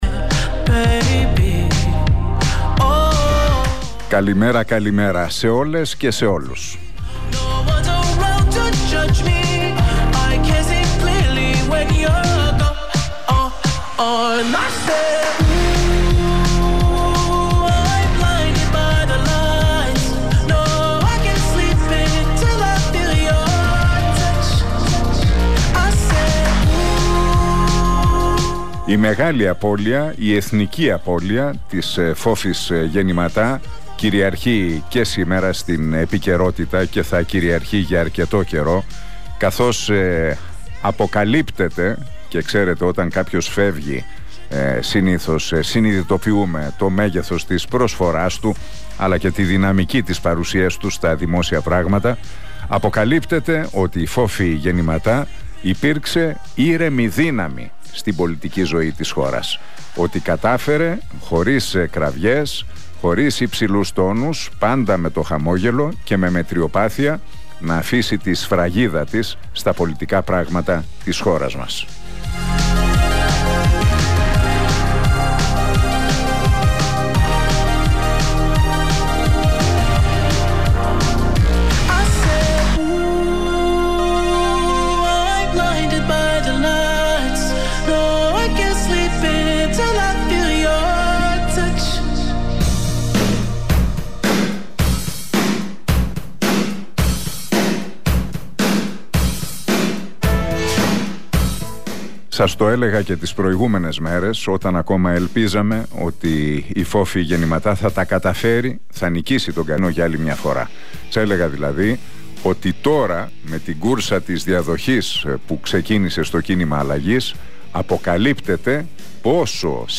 Ακούστε το σημερινό σχόλιο του Νίκου Χατζηνικολάου στον Realfm 97,8.